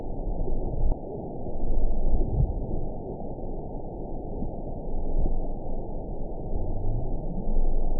event 920702 date 04/04/24 time 16:12:43 GMT (1 year ago) score 8.42 location TSS-AB07 detected by nrw target species NRW annotations +NRW Spectrogram: Frequency (kHz) vs. Time (s) audio not available .wav